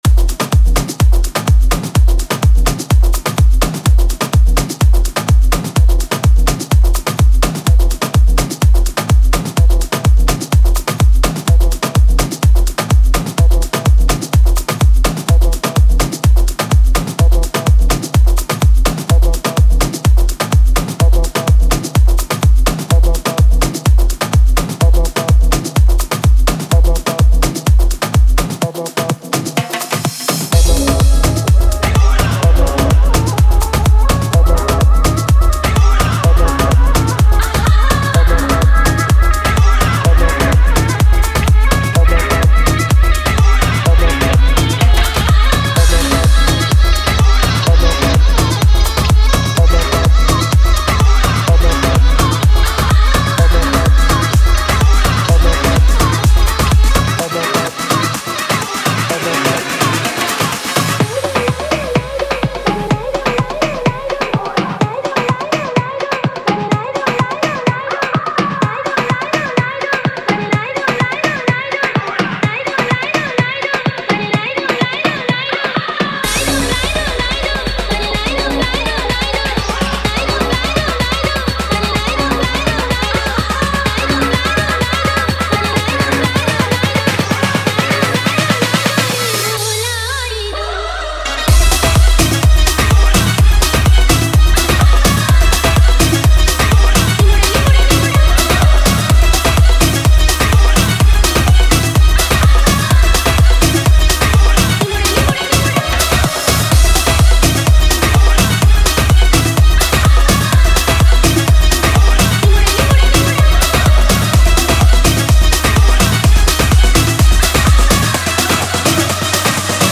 Retro Single Remixes